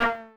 New UI SFX
ui_cancel_v1.wav